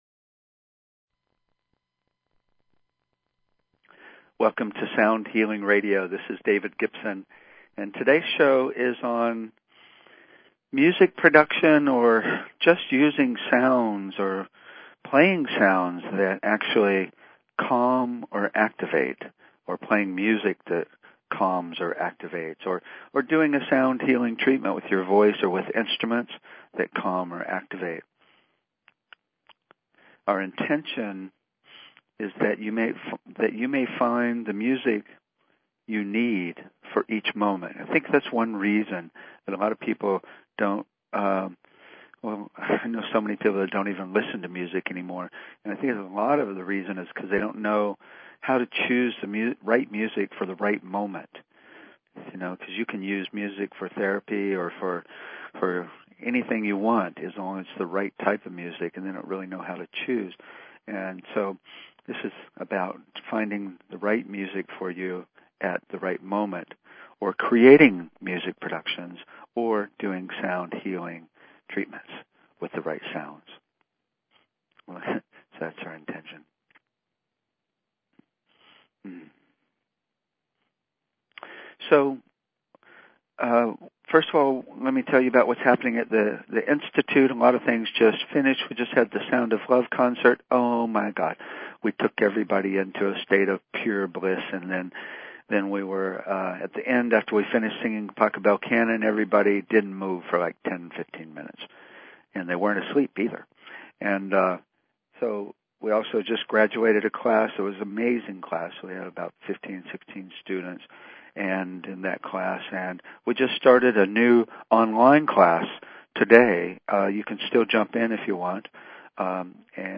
Talk Show Episode, Audio Podcast, Sound_Healing and Courtesy of BBS Radio on , show guests , about , categorized as
CREATING CALMING VS. ACTIVATING DYNAMICS IN A MUSICAL PRODUCTION OR A TREATMENT We'll be discussing and listening to a variety of dynamics that can be created with sound, frequencies, timbres, musical intervals, musical and recording dynamics. These techniques are also useful when choosing what sounds to make with your voice, or what instruments you might use in a sound healing session.